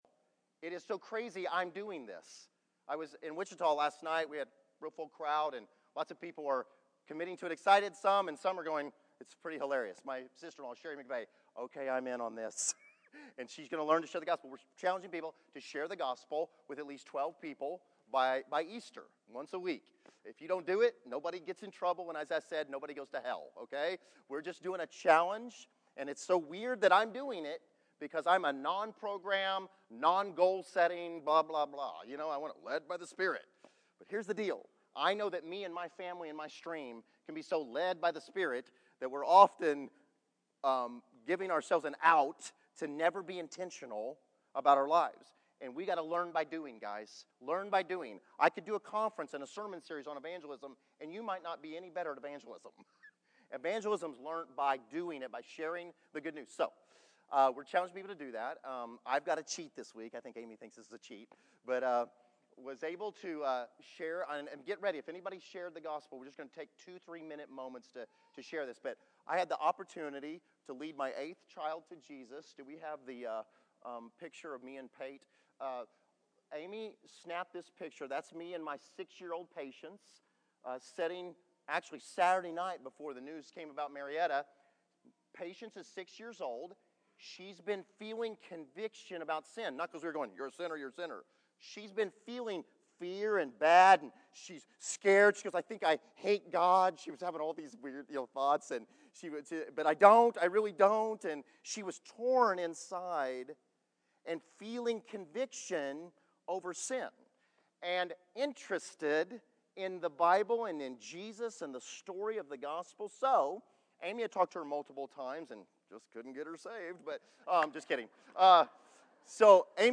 El Dorado Back to the Resource Library Multiple people in the body share evangelism stories.